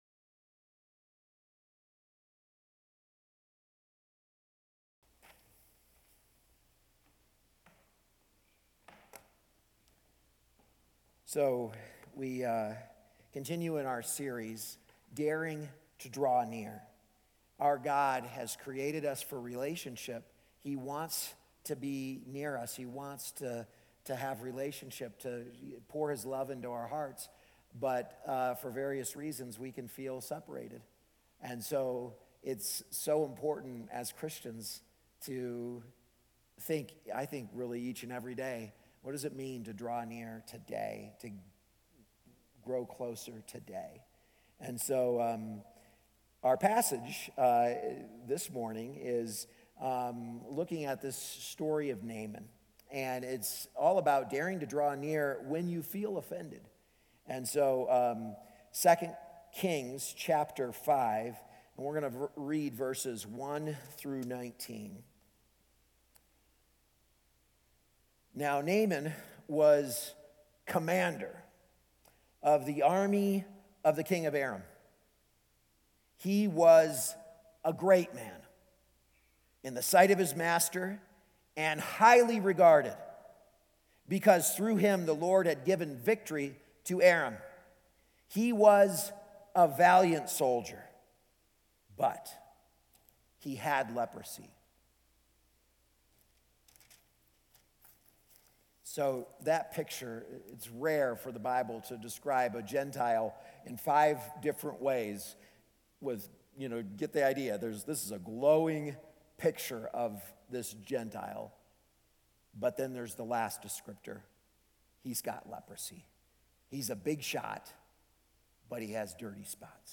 A message from the series "Daring to Draw Near."